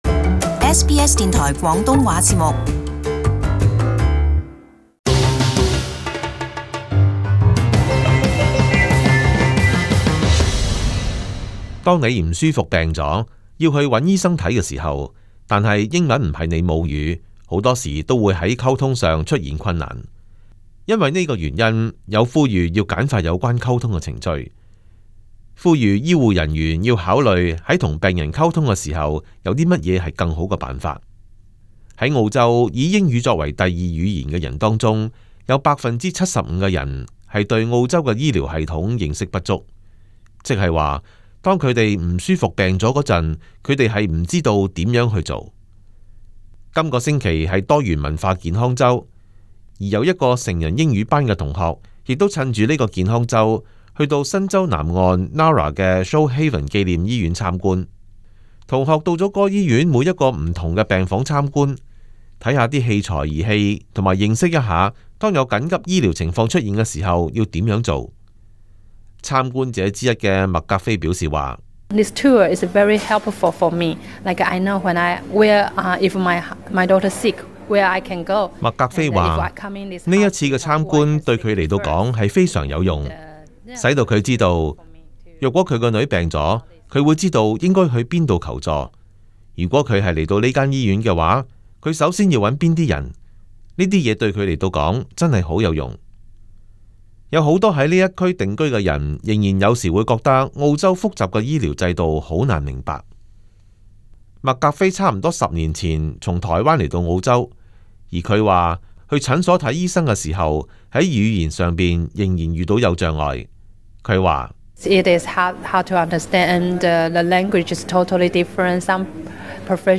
【時事報導】改善病人與醫護人員的溝通